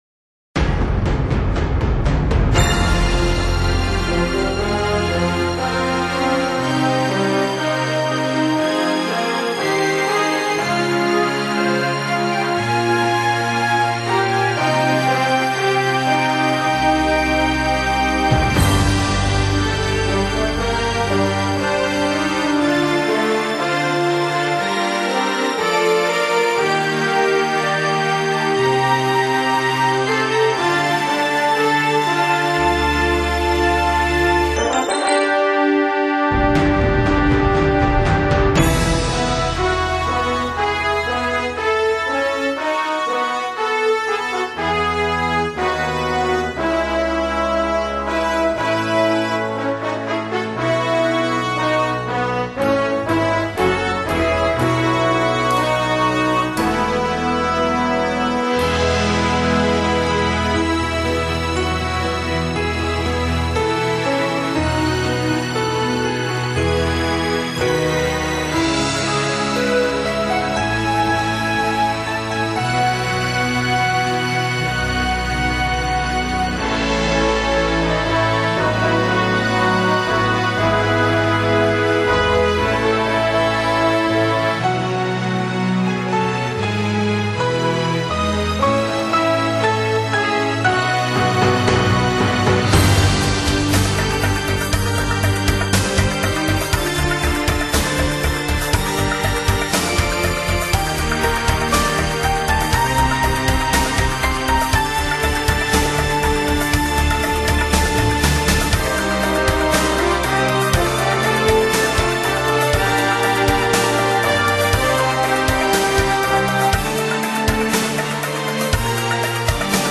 专辑的深度和穿透力都很强，音场相当宽